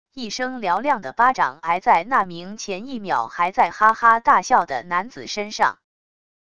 一声嘹亮的巴掌挨在那名前一秒还在哈哈大笑的男子身上wav音频